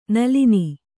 ♪ nalini